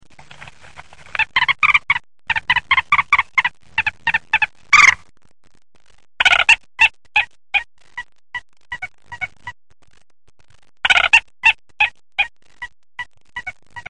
Gallinella d’acqua – Garàtola,Viatàra
Gallinula chloropus
Richiamo un acuto e gracchiante ‘krrrik’ e ‘kittik’.
galinella-d-acqua.mp3